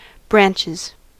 Ääntäminen
Ääntäminen US Haettu sana löytyi näillä lähdekielillä: englanti Käännös Substantiivit 1.